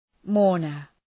{‘mɔ:rnər}